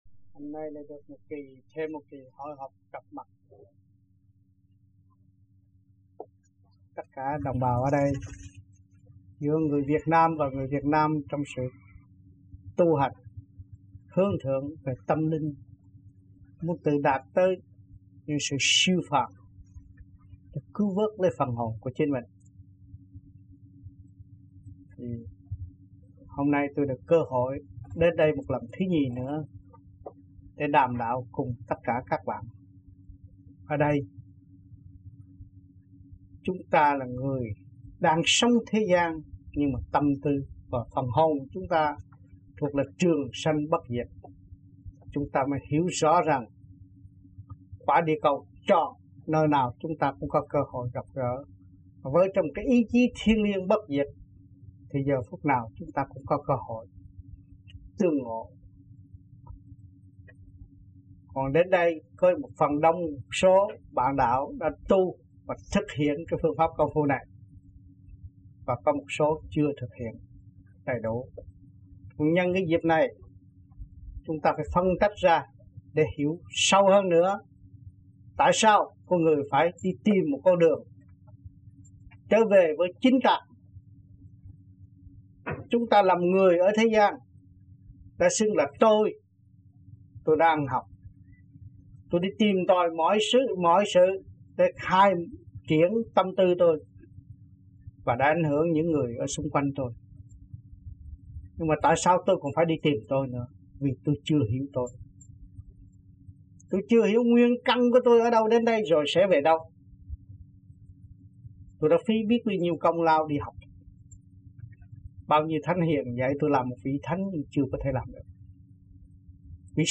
1980-11-11 - NANTERRE - THUYẾT PHÁP 1